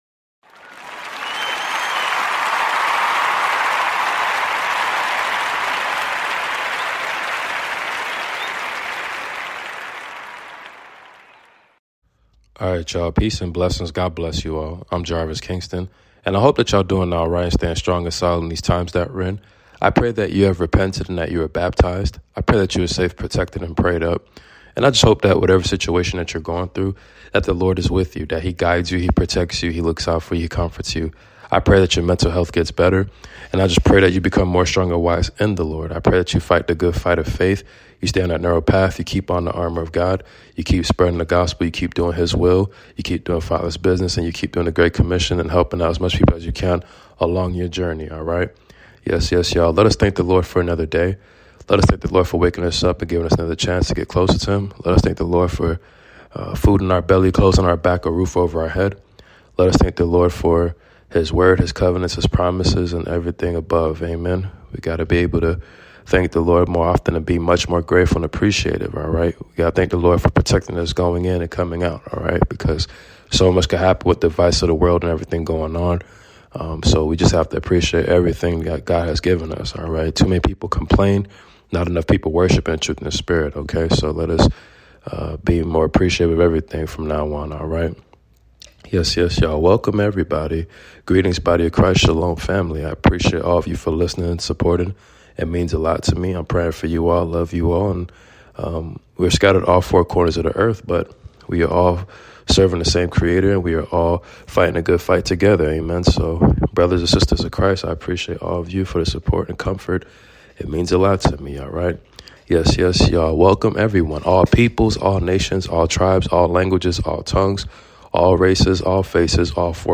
Book of 1 Peter reading completion chapters 1-5 ! Amen !